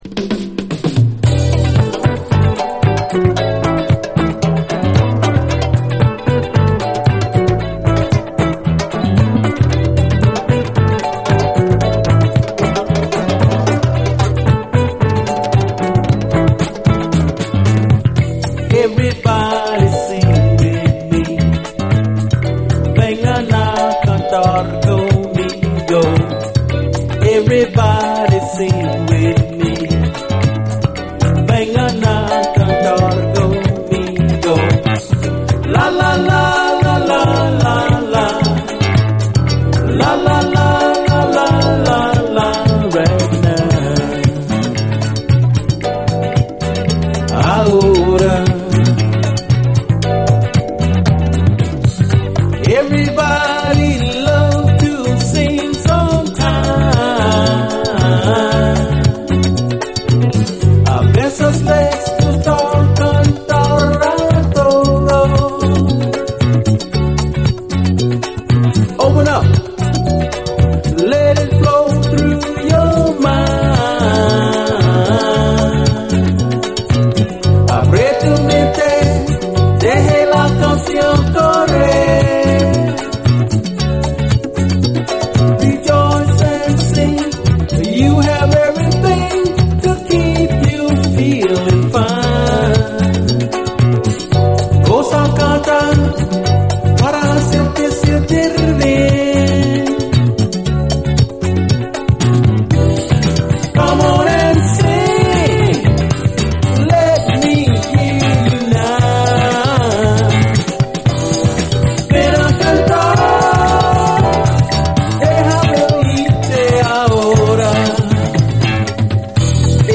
SOUL, 70's～ SOUL, LATIN
レア・チカーノ・モダン・ソウル！
英語とスペイン語を交互に歌う趣向もタマンナイです。
同様にこちらも英語とスペイン語を交互に交えて歌ってます。